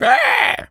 pgs/Assets/Audio/Animal_Impersonations/pig_scream_short_04.wav at master
pig_scream_short_04.wav